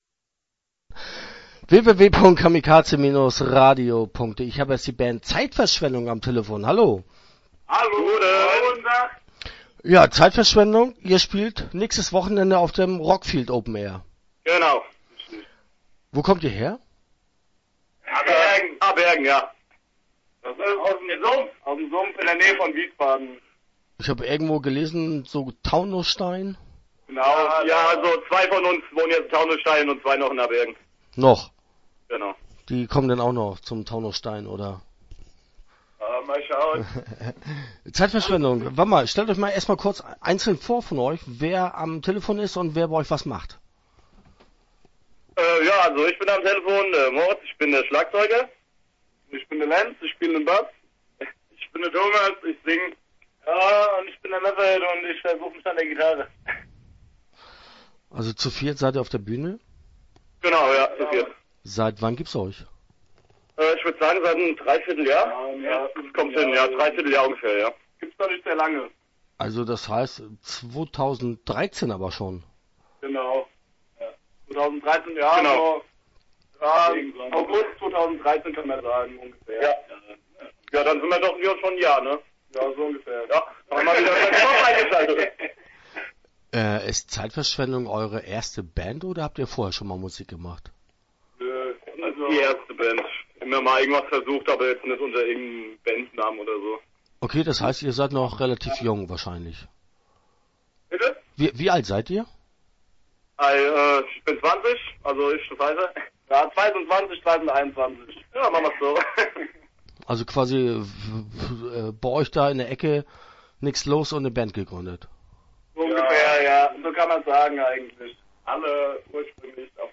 Start » Interviews » Zeit Verschwendung